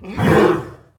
CosmicRageSounds / ogg / general / combat / creatures / horse / he / attack2.ogg